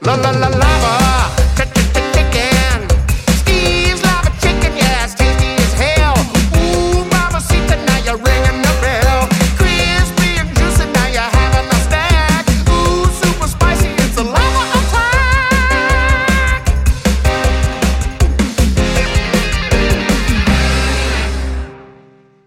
alarm6.wav